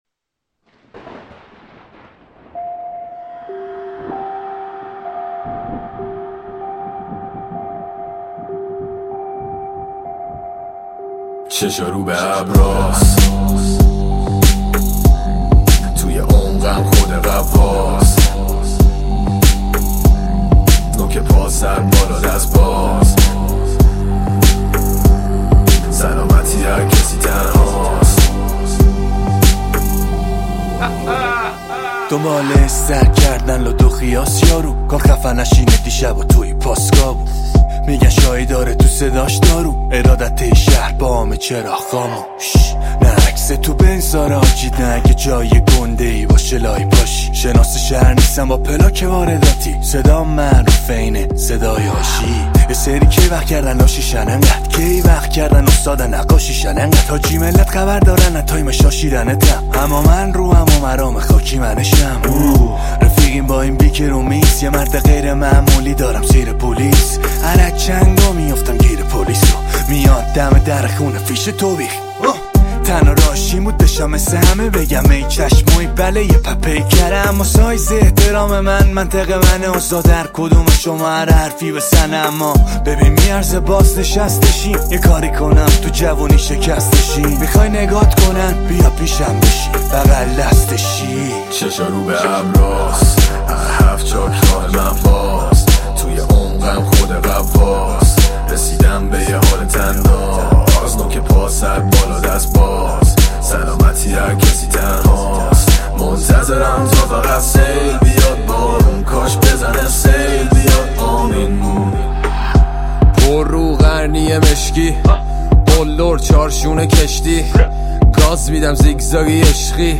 تک آهنگ
هیپ هاپ